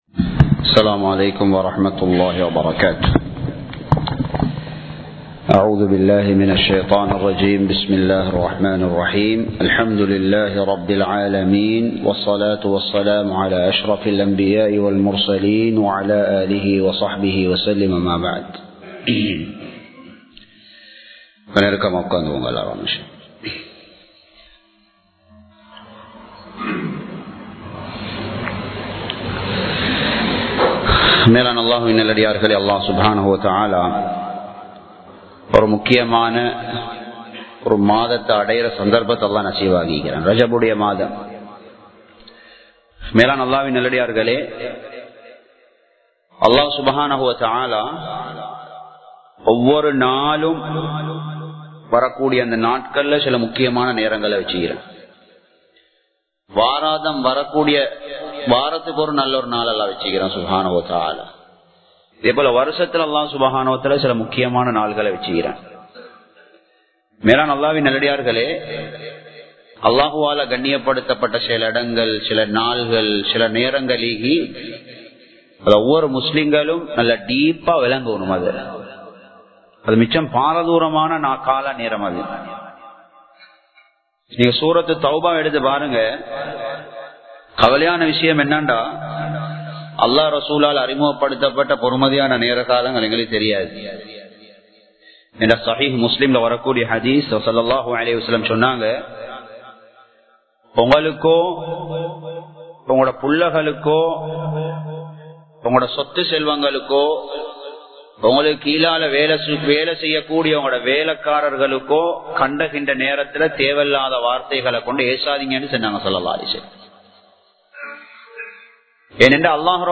Muhiyadeen Jumua Masjith